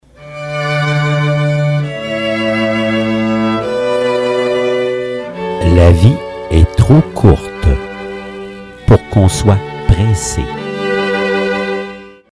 Musique de fond; Le canon de Pachelbel ( violons en 432 htz )
( la qualité sonore est variable… )